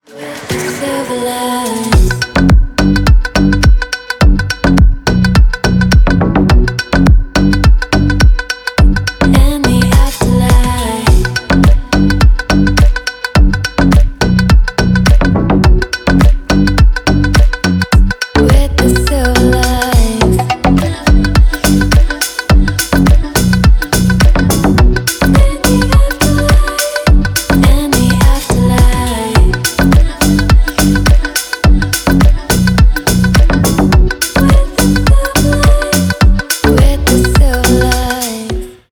• Качество: 320, Stereo
восточные мотивы
dance
Electronic
красивый женский голос
house
Стиль: tribal trap